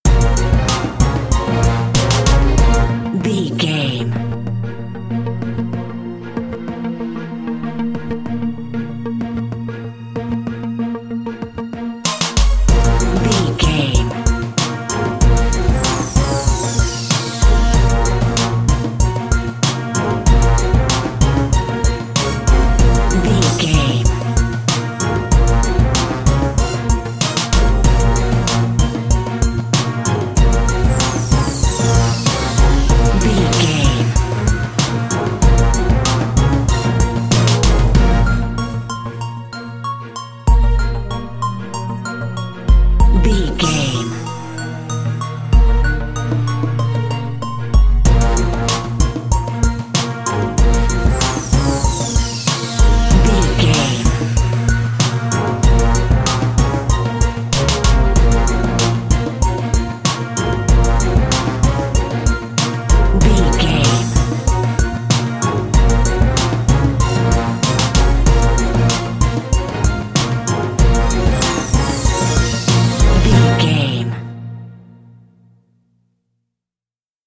Aeolian/Minor
B♭
drums
percussion
strings
conga
brass